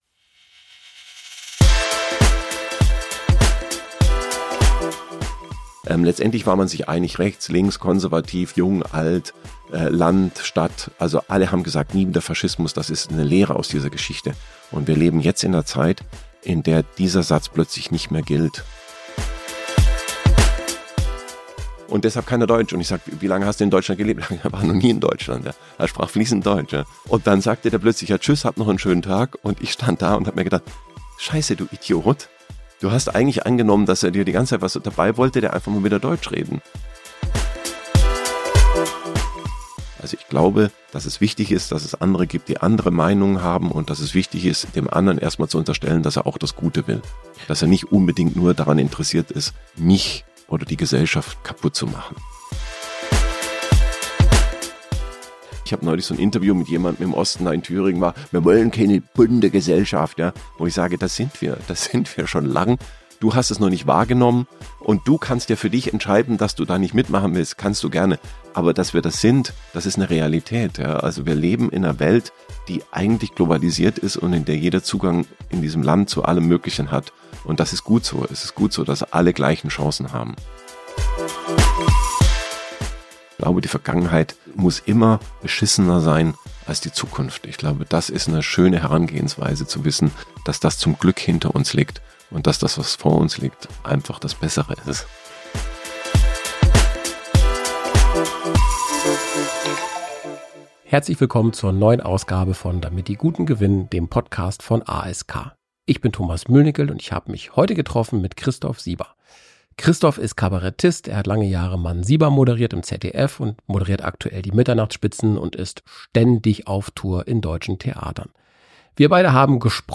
mit dem Kabarettisten Christoph Sieber. Es geht um die politischen und gesellschaftlichen Herausforderungen unserer Zeit.